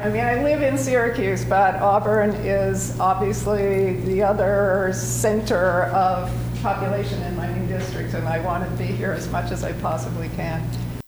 State Senator Rachel was at Auburn’s City Council meeting Thursday night for her ceremonial swearing in as the senator for New York’s 48th senate district.